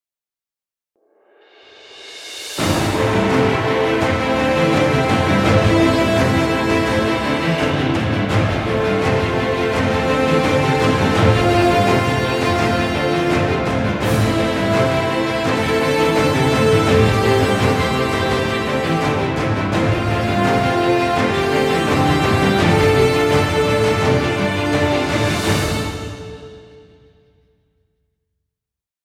Trailer music, exciting intro, or battle scenes.